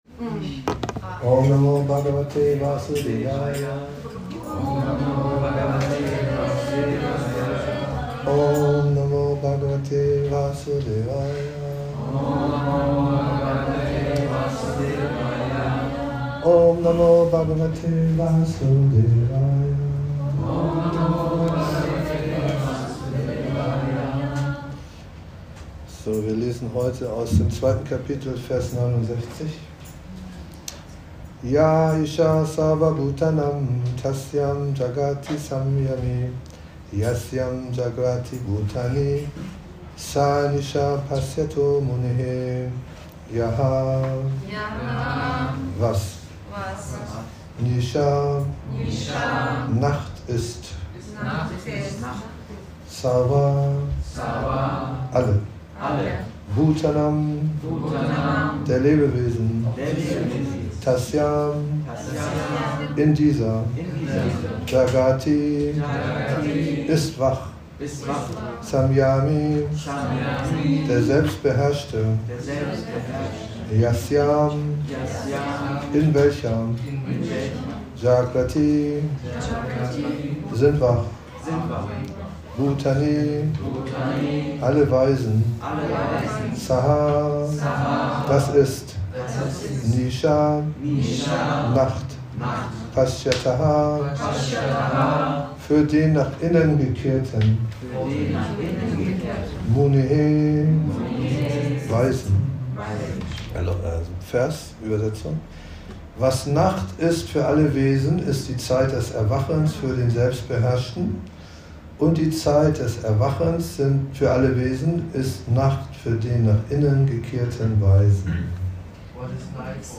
Der innere und äußere Weg - Vortrag